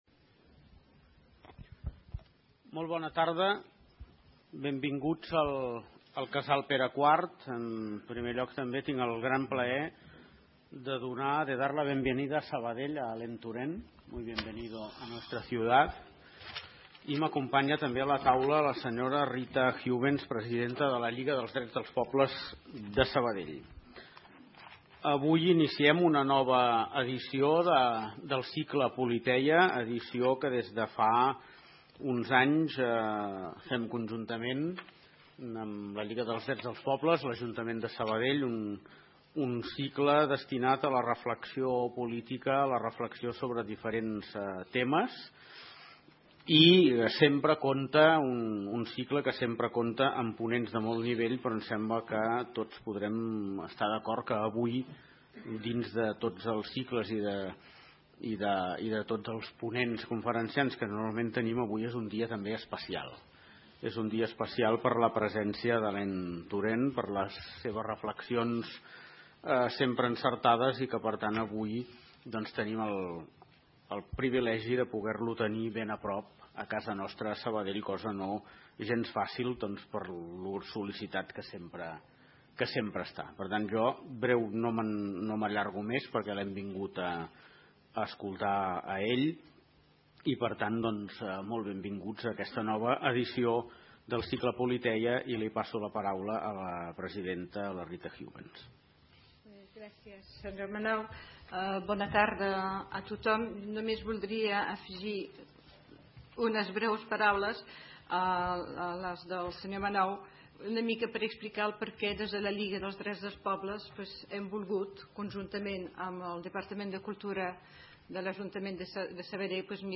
* El audio tiene una breve presentación en Catalán, Alain Touraine imparte su conferencia en idioma español